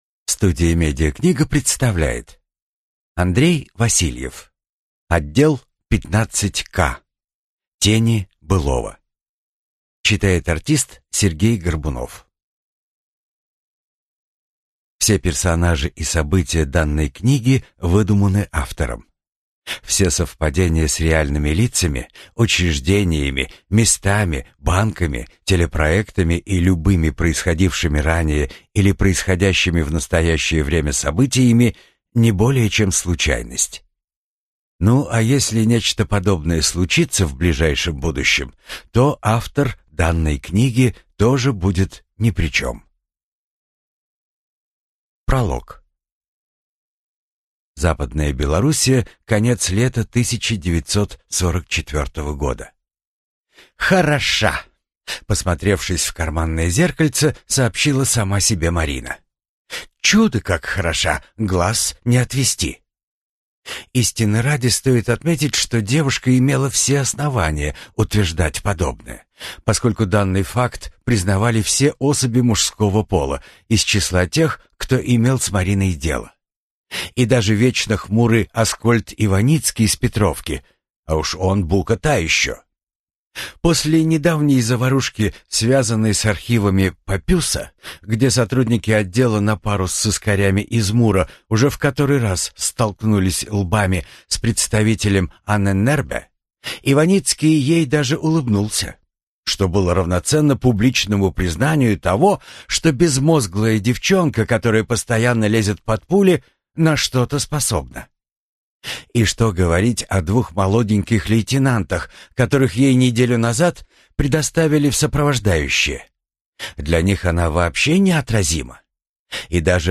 Студия «МедиаКнига» представляет вторую аудиокнигу уже снискавшую популярность серии «Отдел 15-К» известного российского писателя Андрея Васильева – «Отдел 15-К. Тени Былого».